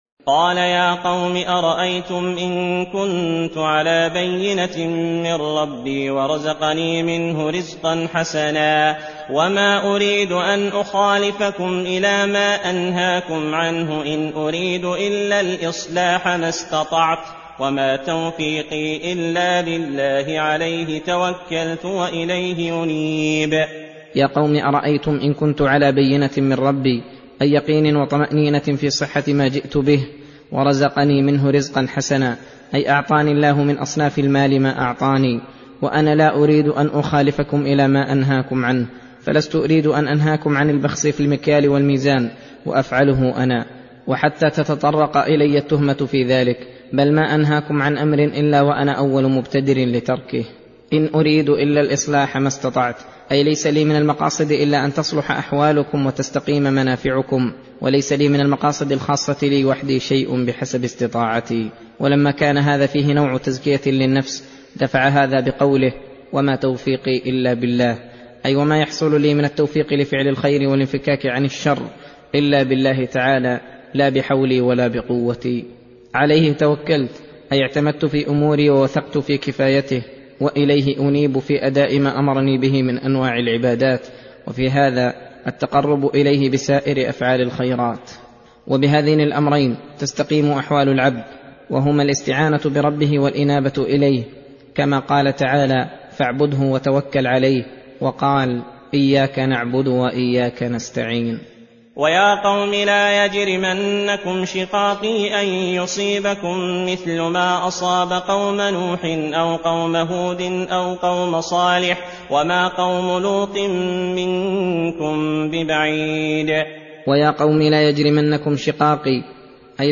درس (35): تفسير سورة هود : (88- 107)